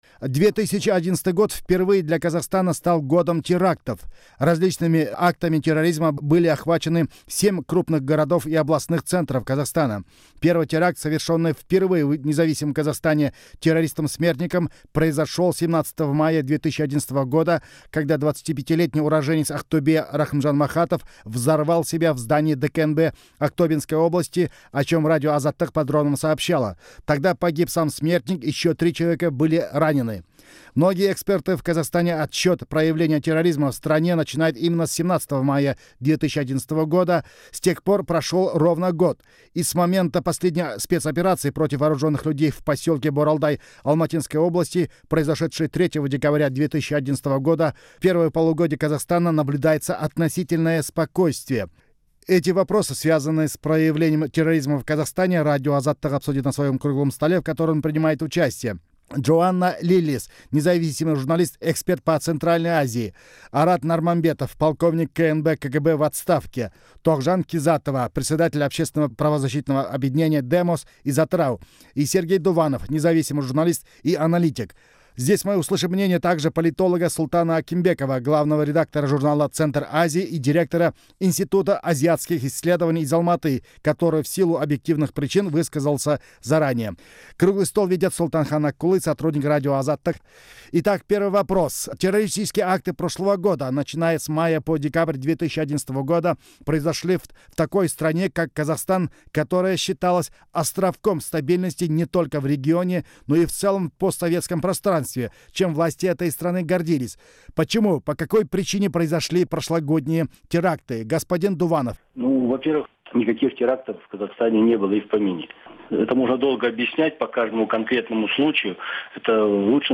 Запись круглого стола